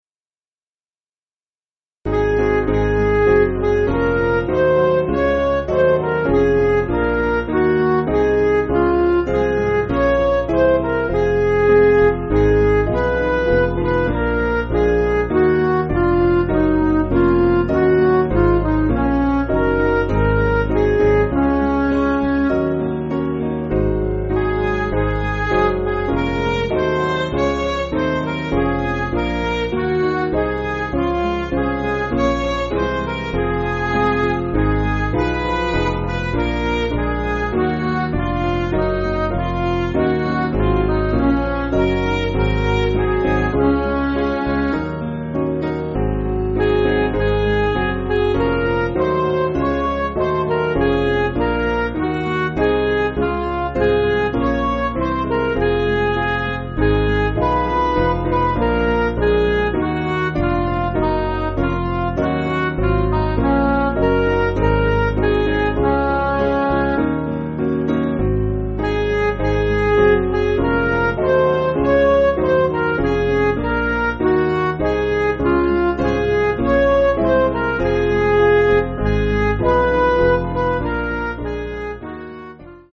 Piano & Instrumental
(CM)   6/Db